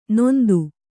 ♪ nōndu